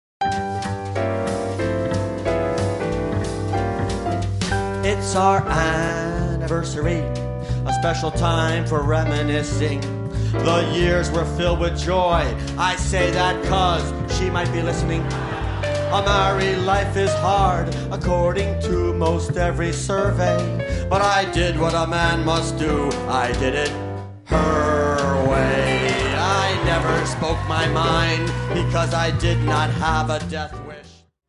--comedy music and standup